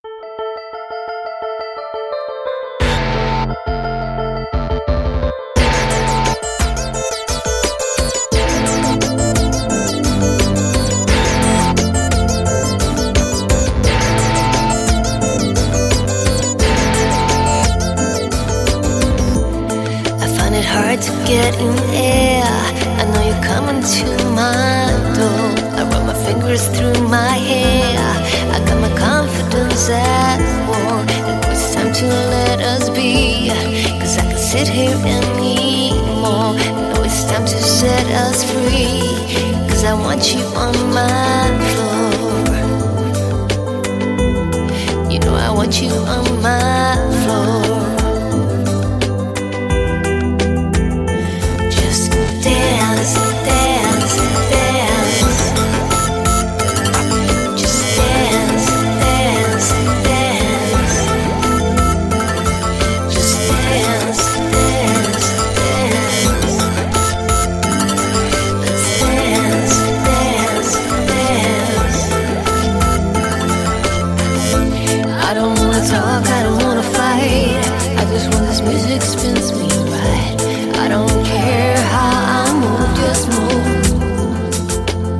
sång, gitarr, slagverk, klaviatur & programmering